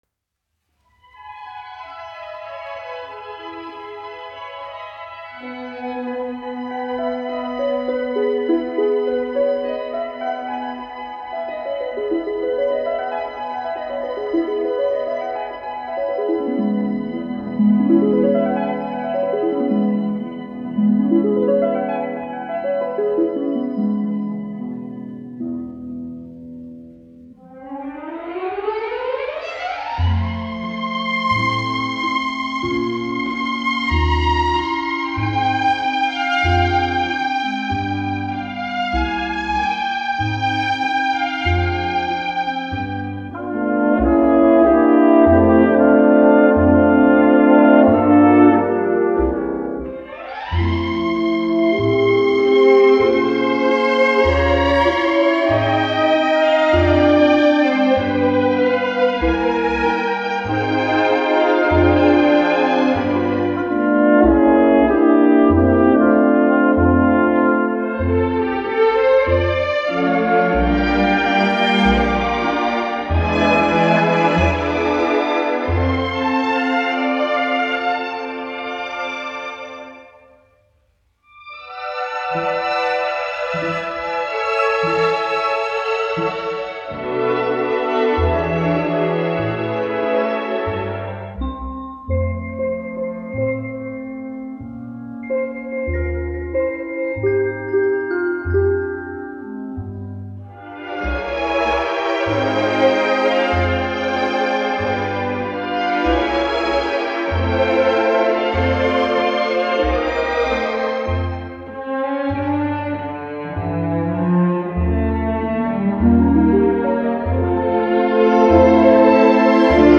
1 skpl. : analogs, 78 apgr/min, mono ; 25 cm
Populārā instrumentālā mūzika
Latvijas vēsturiskie šellaka skaņuplašu ieraksti (Kolekcija)